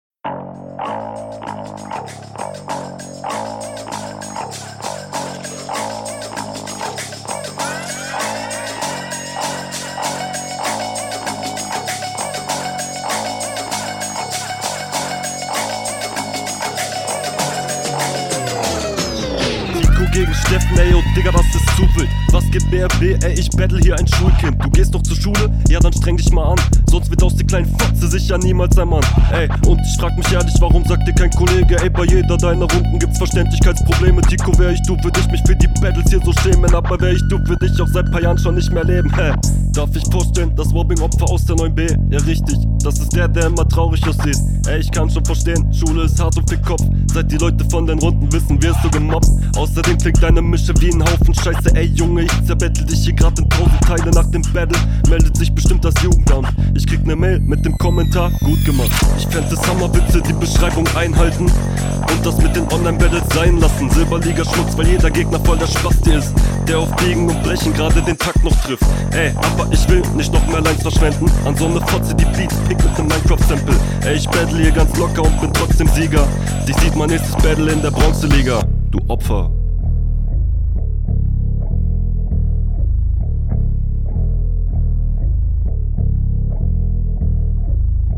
Das ist in meinen Ohren ein geeigneter beat zum battlen, wunderbar.
patterns wieder sehr cool, wie gesagt, mische nicht so nice Aber sei mal am besten …